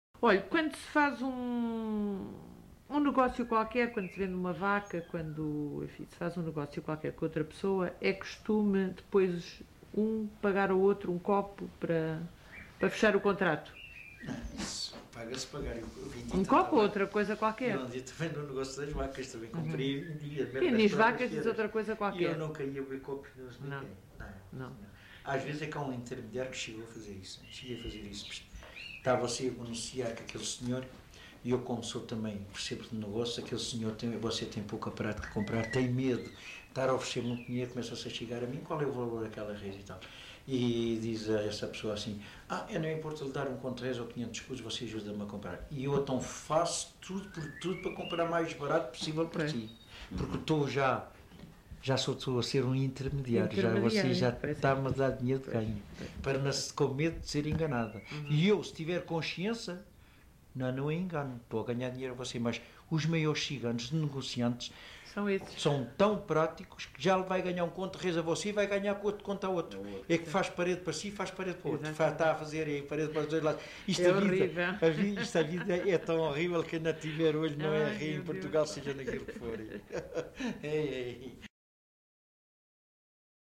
LocalidadeEnxara do Bispo (Mafra, Lisboa)